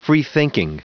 Prononciation du mot freethinking en anglais (fichier audio)
Prononciation du mot : freethinking